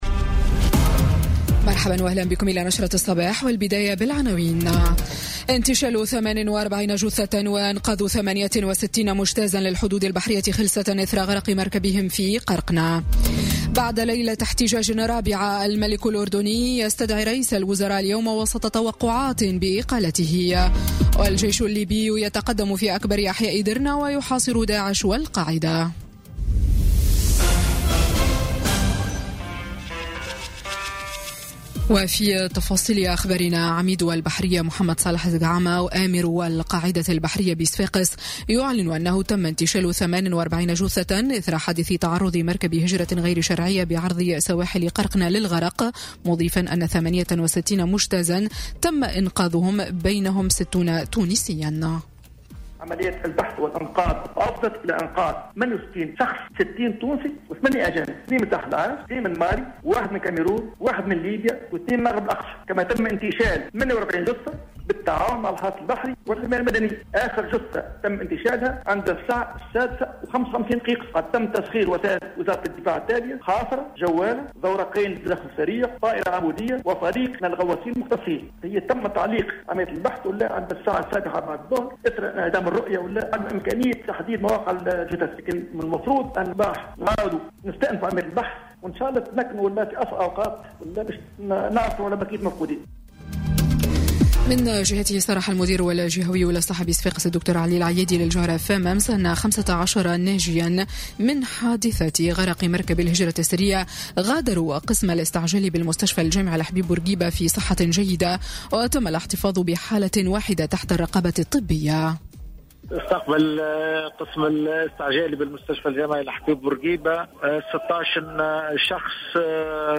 نشرة أخبار السابعة صباحا ليوم الإثنين 04 جوان 2018